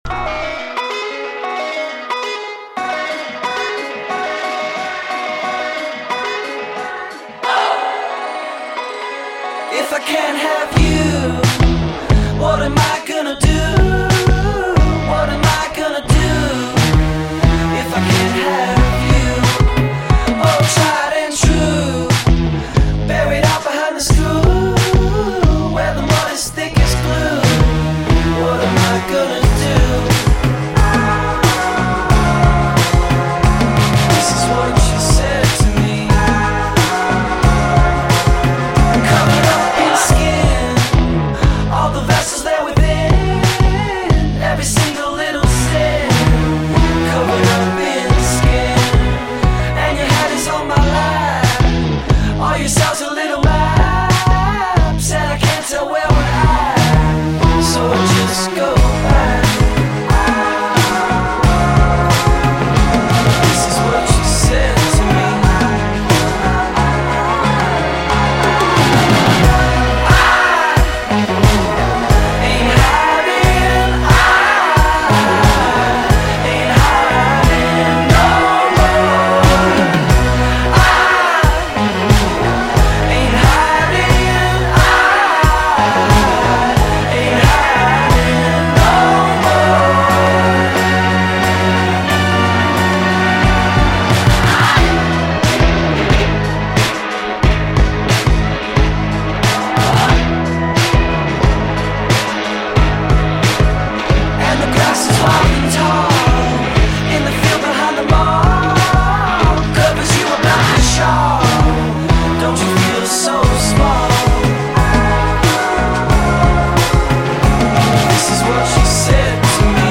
Indie Indie rock Indie pop